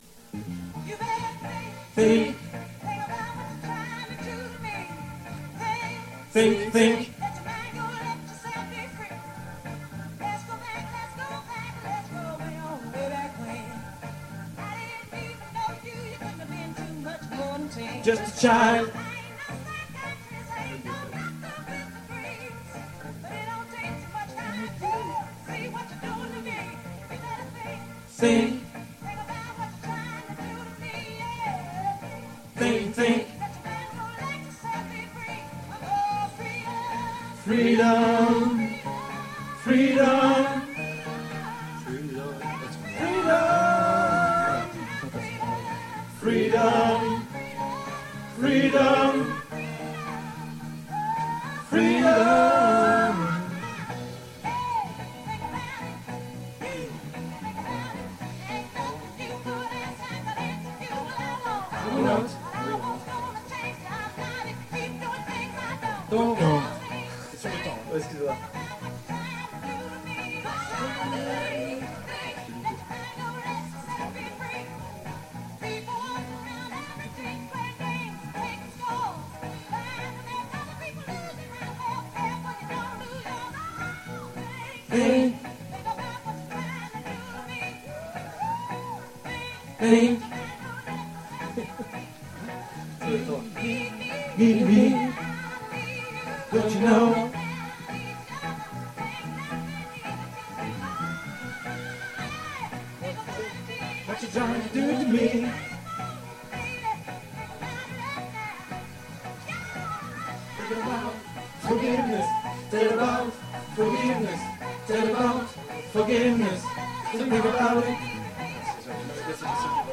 Nos choeurs... pour travail Sep 23
choeurs_Think.mp3